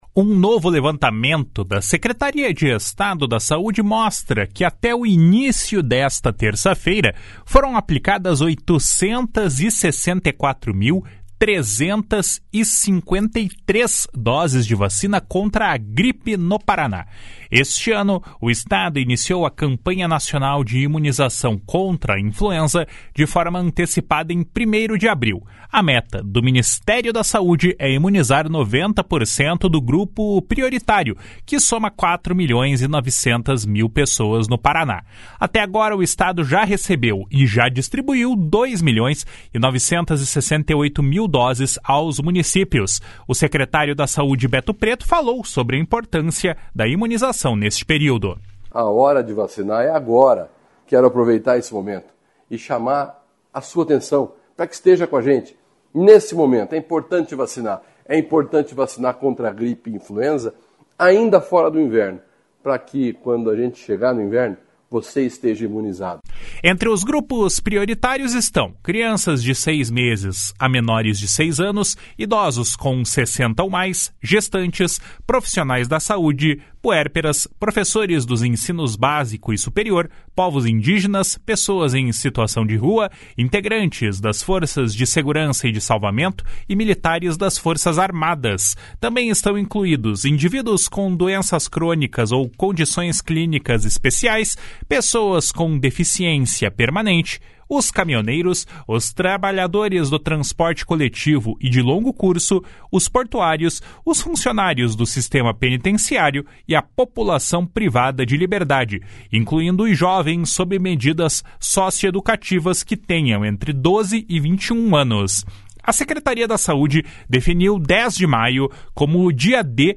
O secretário da Saúde, Beto Preto, falou sobre a importância da imunização neste período. // SONORA BETO PRETO //